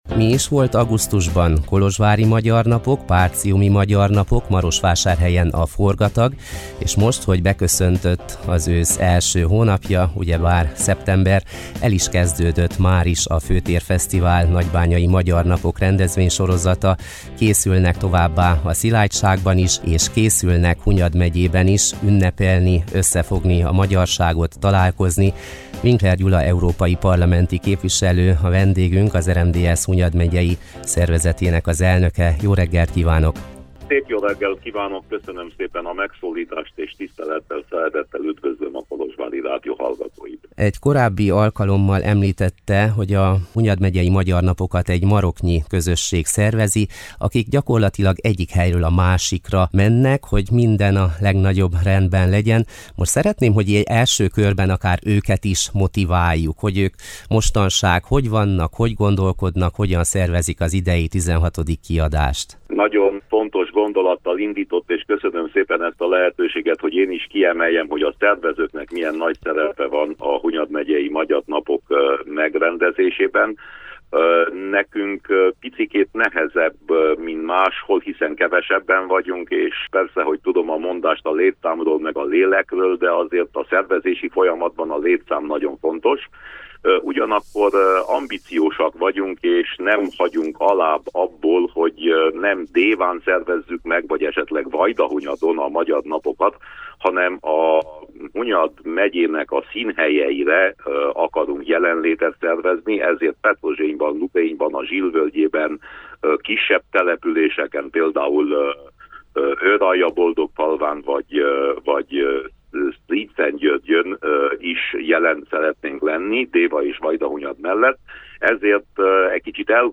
A Hangolóból tárcsáztuk Winkler Gyula európai parlamenti képviselőt, az RMDSZ Hunyad megyei elnökét.